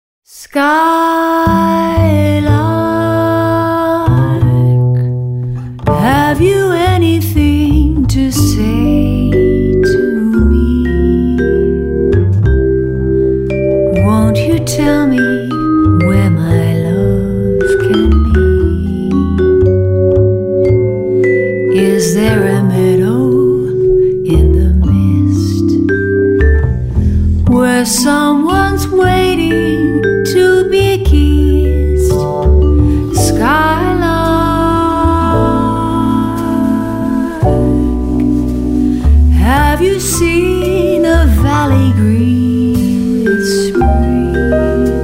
vocal&bass
vibraphone
piano
Recorded at Avatar Studio in New York on March 23 & 24, 2011